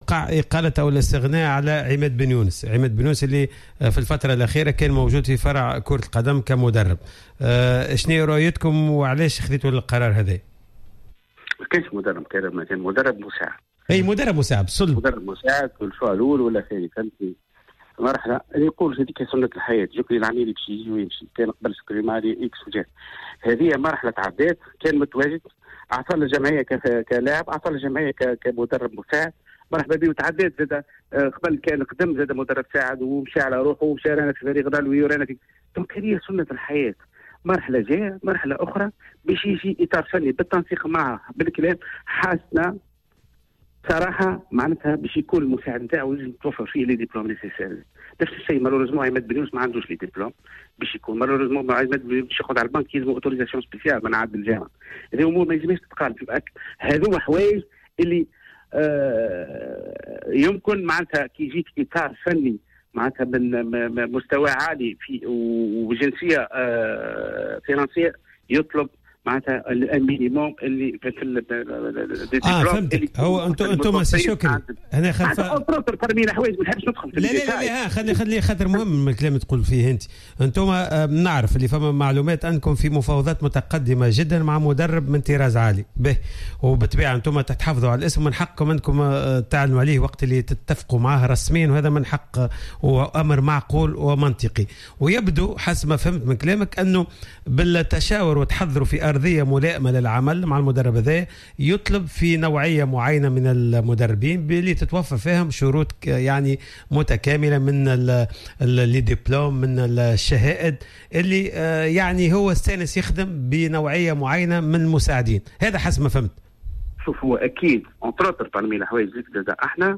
في مداخلة في حصة "راديو سبور" حول القرارات المتخذة مؤخرا من قبل الهيئة المديرة و التي تخص الإطار الفني للفريق.